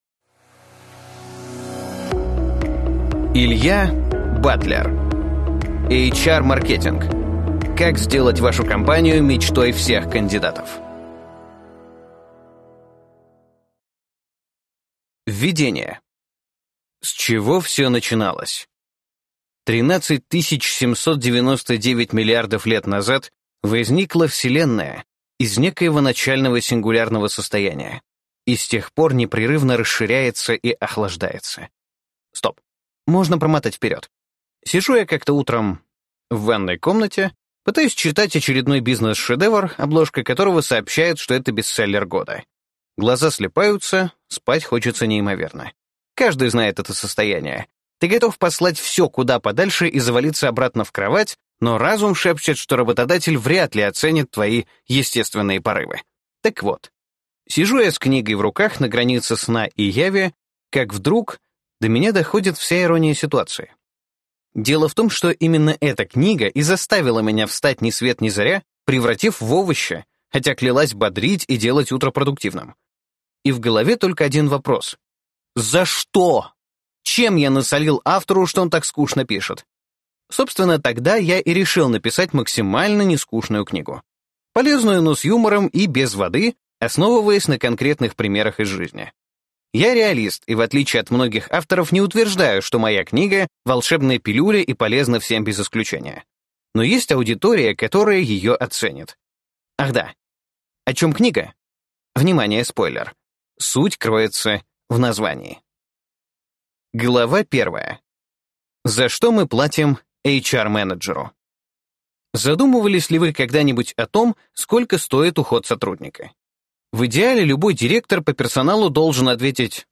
Аудиокнига HR-маркетинг. Как сделать вашу компанию мечтой всех кандидатов | Библиотека аудиокниг